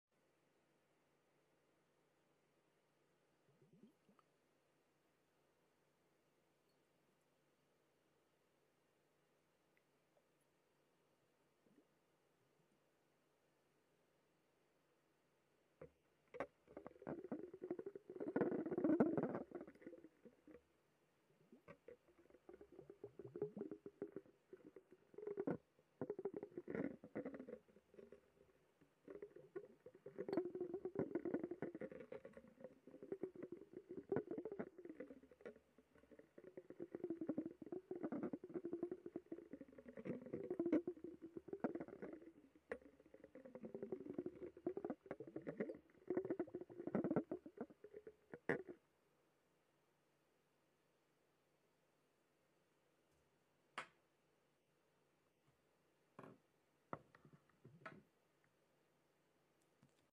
✨💆‍♀ In this video, enjoy the soothing sounds of Space and planets, designed to help you relax, unwind, and melt away stress. 🔊 Best experienced with headphones!